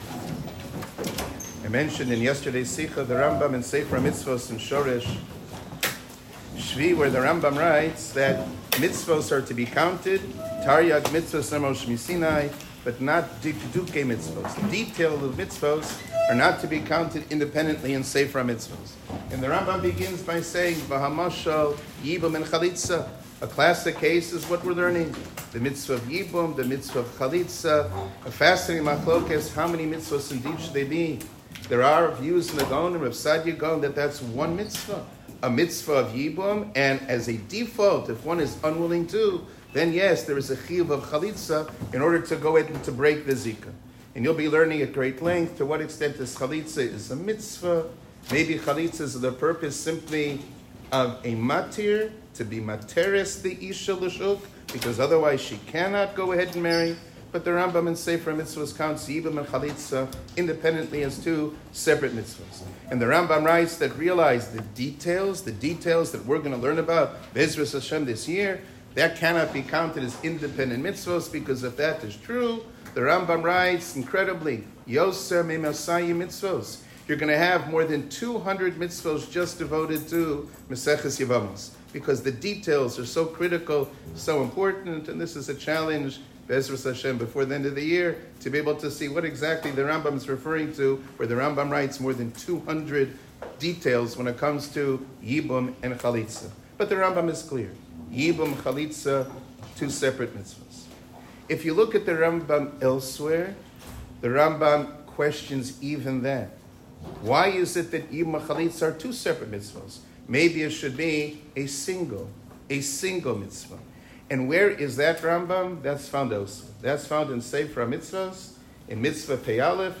שיעור כללי - פתיחה למסכת יבמות